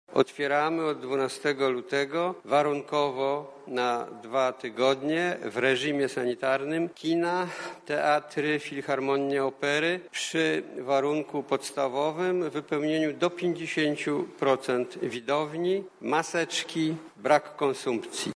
-mówi Minister Kultury i Dziedzictwa Narodowego Piotr Gliński.